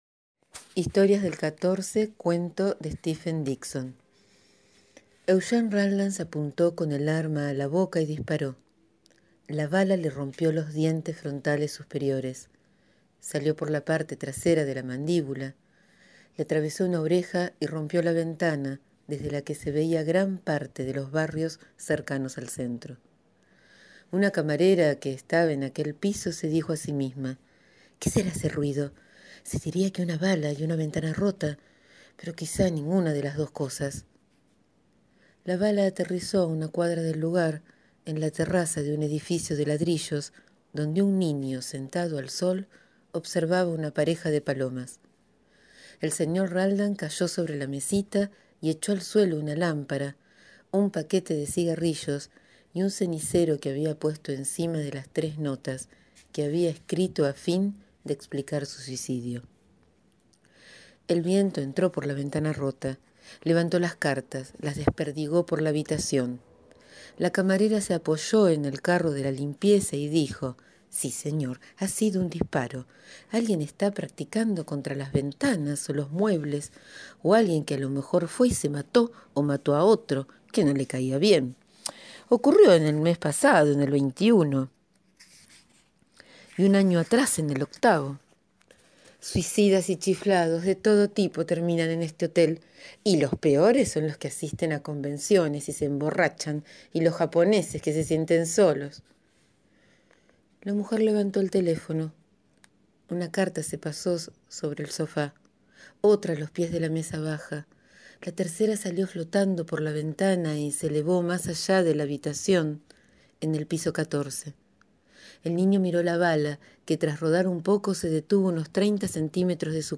Leo hoy «Historias del 14» cuento de Stephen Dixon.